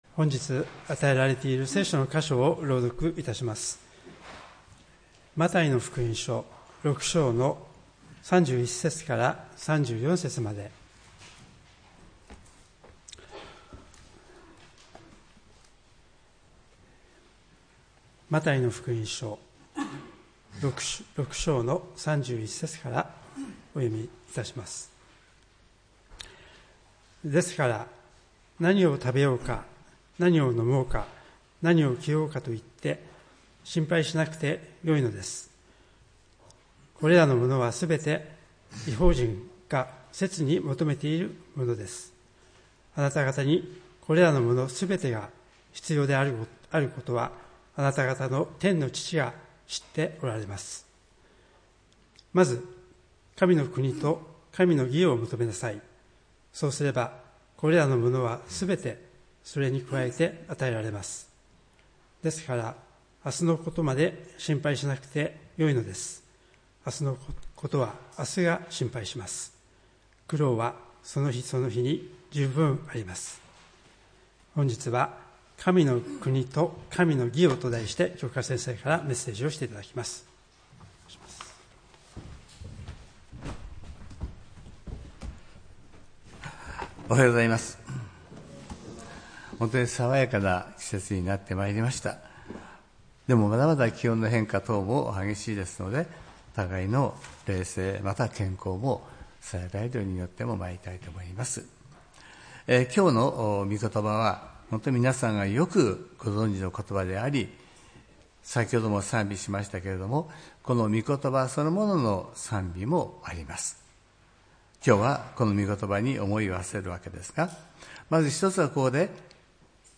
礼拝メッセージ「神の国と神の義を」(５月11日）